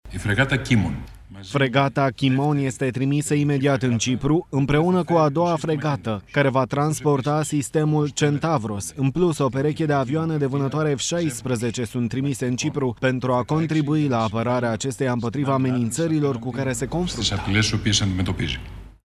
Grecia a anunțat că trimite două fregate și două avioane de vânătoare în Cipru, după atacurile cu drone iraniene asupra unei baze britanice de pe insulă. Una dintre fregate este echipată cu un sistem antidronă, a declarat ministrul grec al Apărării, Nikos Dendias, într-o conferință de presă.
02mar-15-Nikos-Dendias-grecia-trimite-fregate-in-Cipru-tradus.mp3